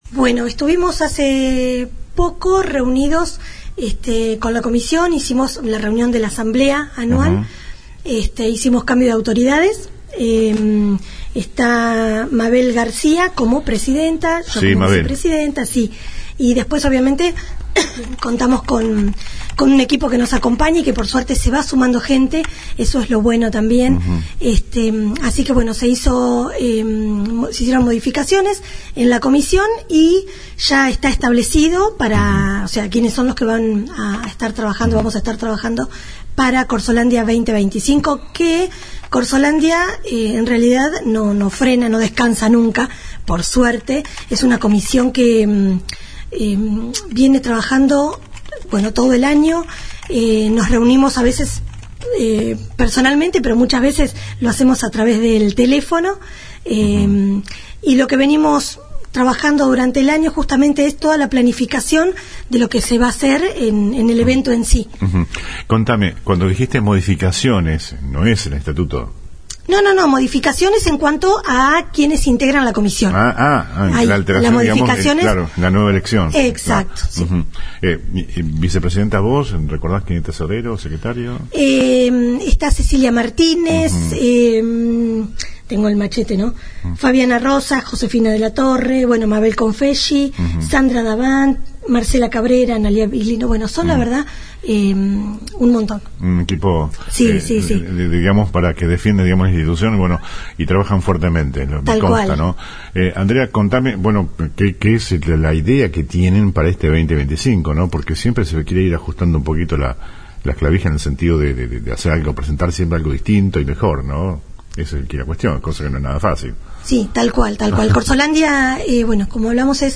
AUDIO COMPLETO DE LA ENTREVISTA